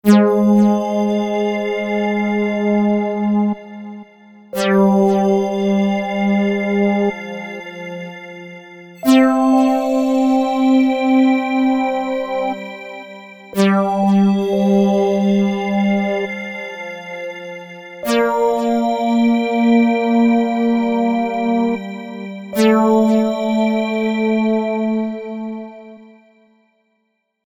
RINGTONE : mp3 cell phone ringtone